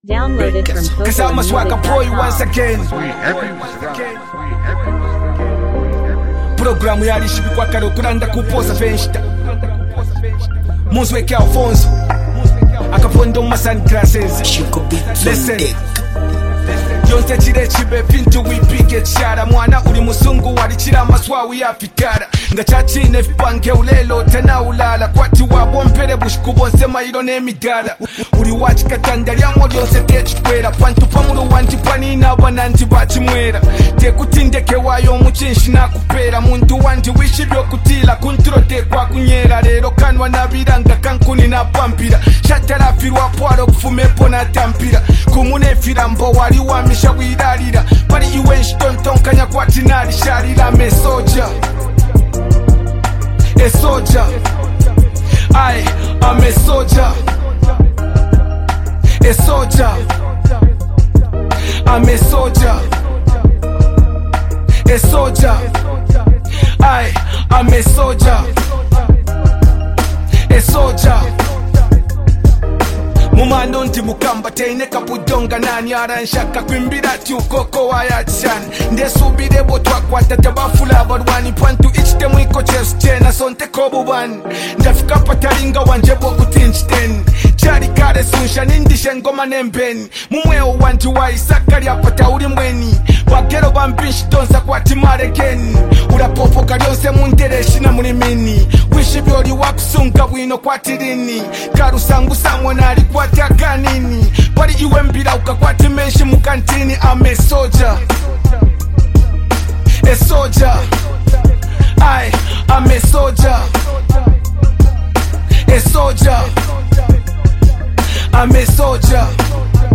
bold, motivational hip-hop track